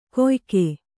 ♪ koyke